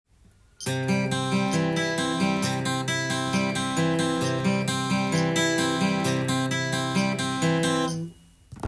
ピックと指弾き強化【ギターで16分音符のアルペジオ練習】
コードはCでアルペジオパターンを譜面にしてみました。